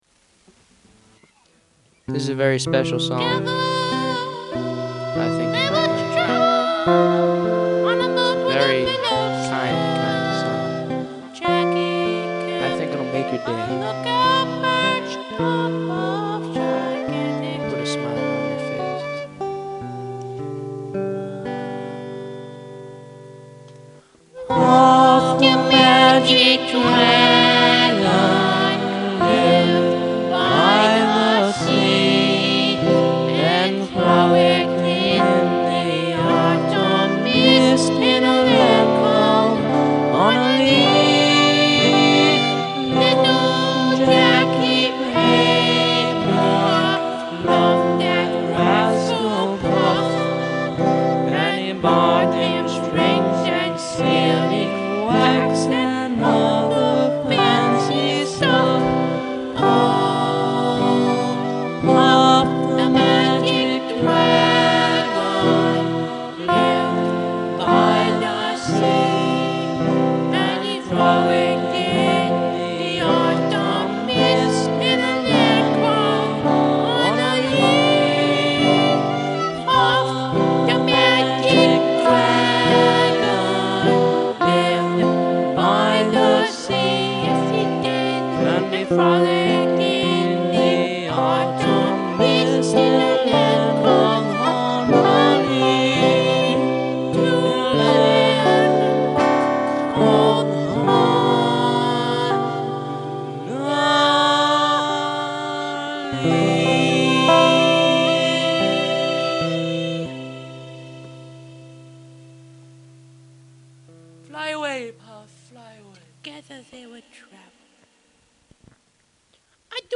Classic
This is a comical version
This is a folk song.
yup! it's funny and i like it